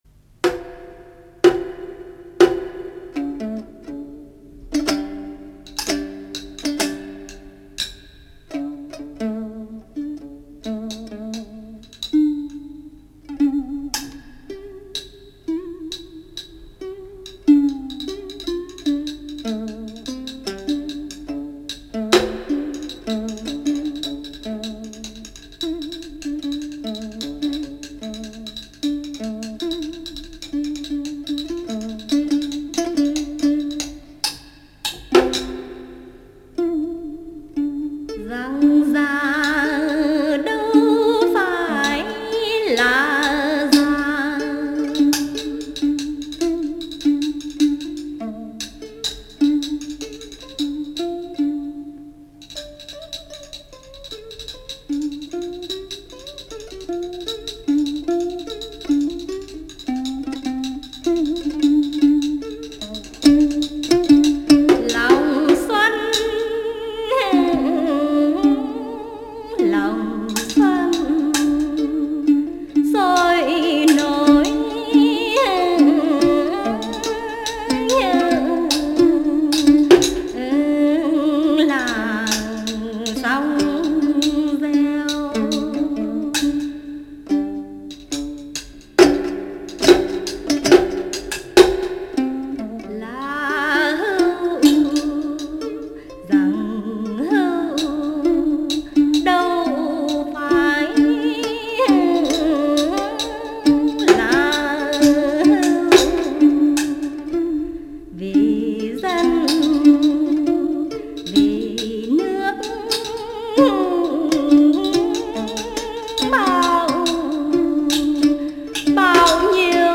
thuộc thể loại Ca trù.